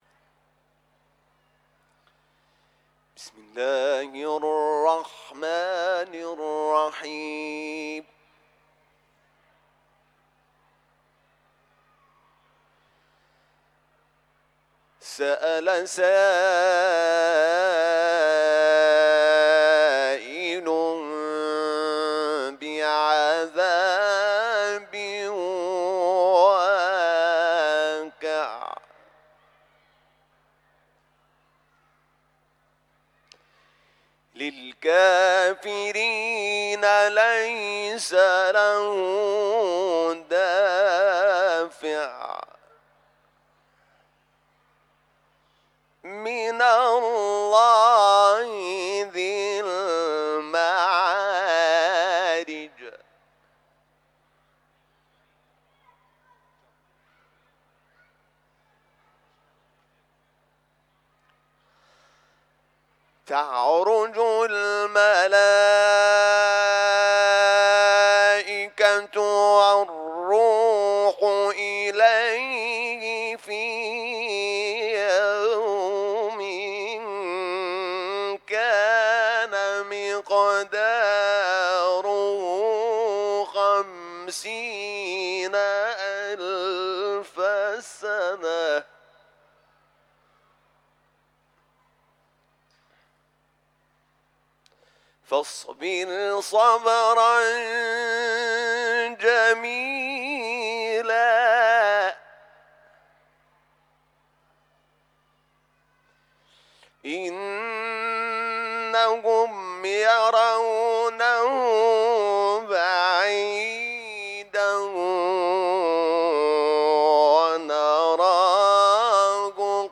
صوت | تلاوت حمید شاکرنژاد از سوره «معارج»
صوت تلاوت آیاتی از سوره‌ «معارج» با صدای حمید شاکرنژاد، قاری بین‌المللی قرآن را در این بخش بشنوید.